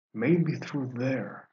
Voicelines